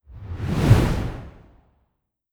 whoosh.wav